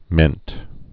(mĕnt)